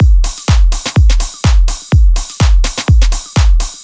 House Sounds " 025 House Studio Ambient
描述：工作室环境声音
标签： 工作室 房子
声道立体声